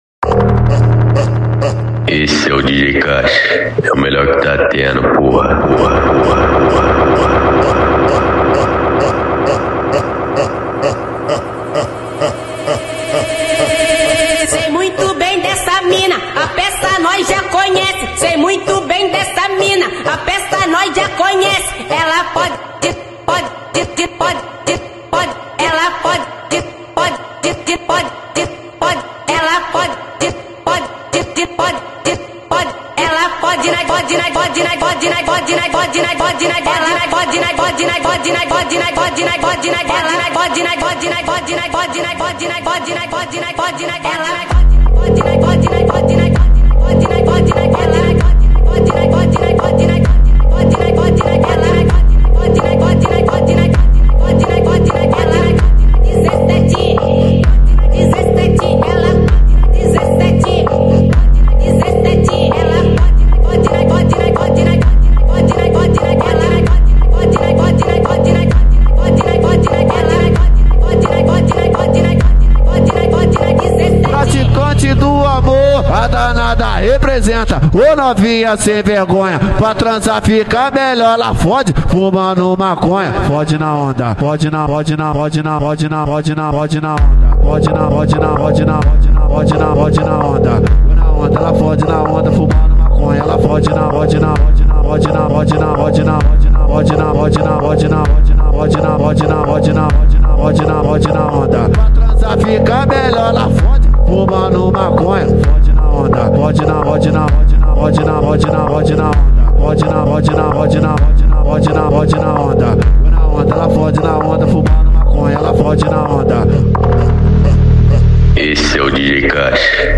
high-energy Brazilian funk song